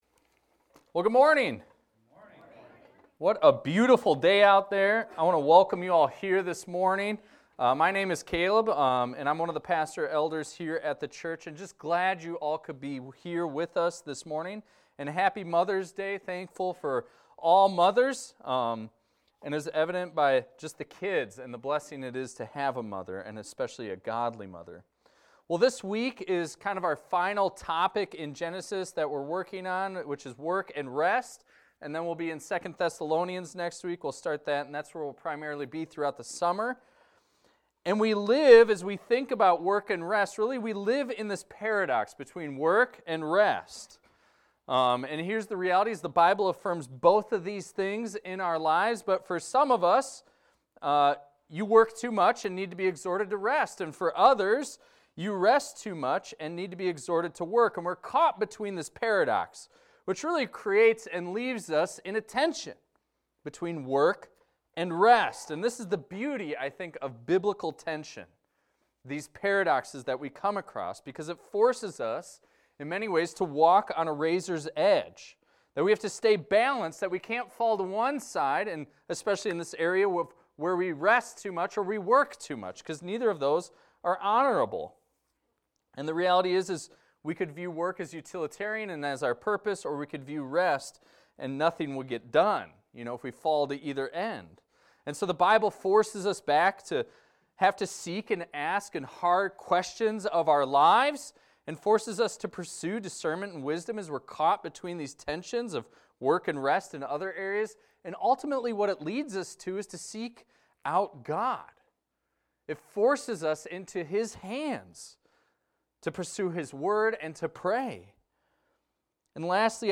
This is a recording of a sermon titled, "God Rested."